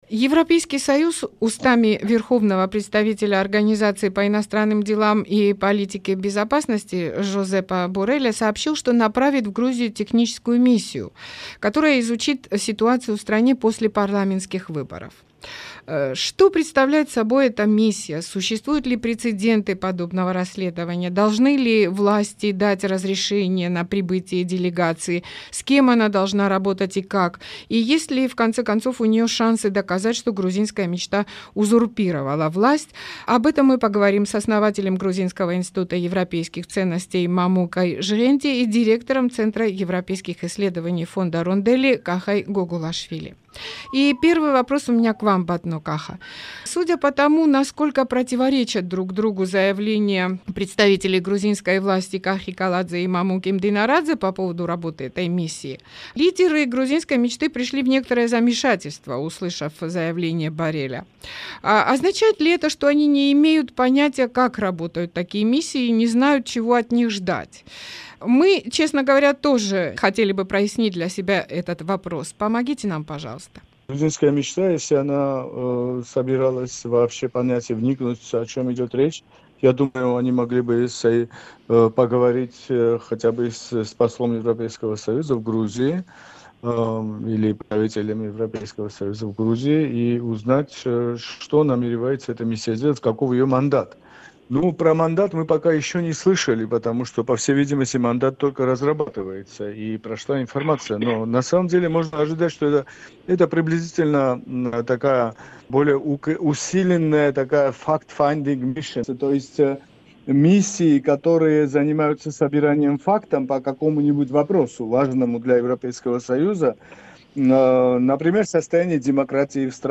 Гости за «Некруглым столом» считают, что протесты в Грузии против фальсификаций на выборах и международное расследование могут вынудить «Грузинскую мечту» назначить повторные выборы